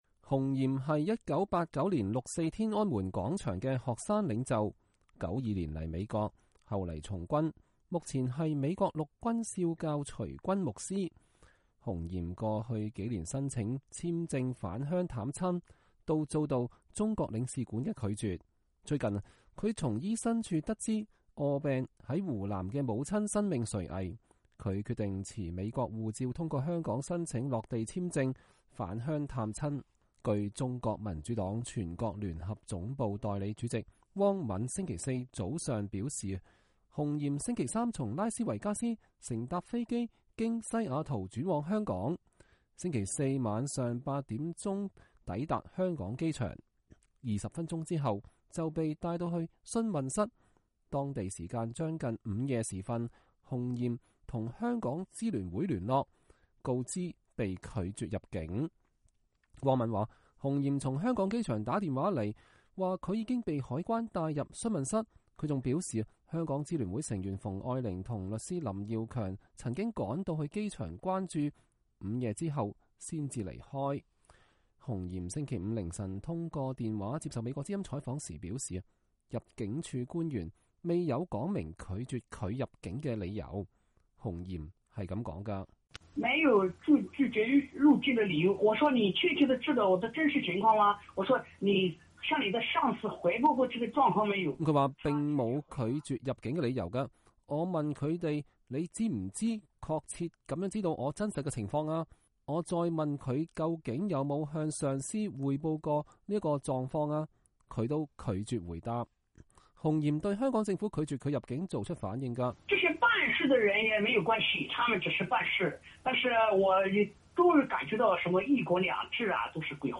他通過電話念出詩句：“親愛的母親，你躺在病床上，已是筋疲力竭，求你寬恕你不孝的兒子，不能送你臨終。我已抵達香港，想象您憔悴的面容，我伸出手來，向您靠近，親愛的媽媽，求您也伸出手來，讓我們在愛的世界里相逢。”